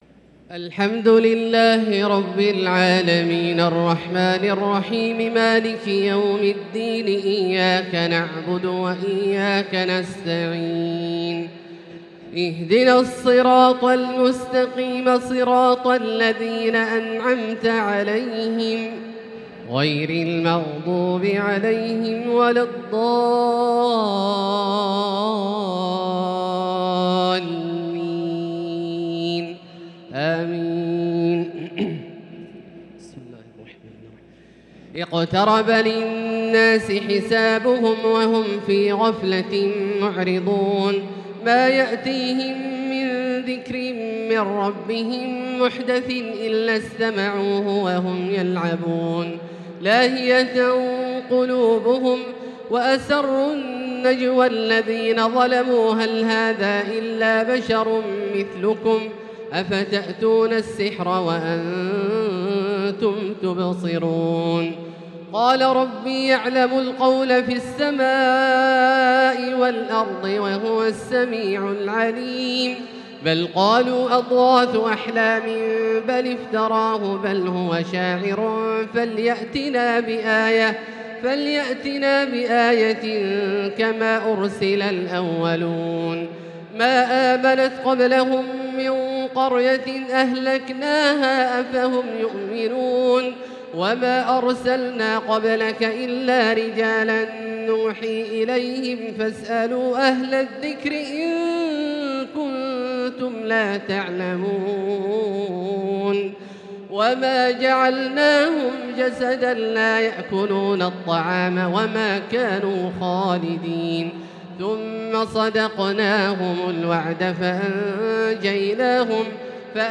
تراويح ليلة 30 رمضان 1443هـ من سورة الأنبياء (1-73) | taraweeh 30st night Ramadan 1443H Surah Al-Anbiya > تراويح الحرم المكي عام 1443 🕋 > التراويح - تلاوات الحرمين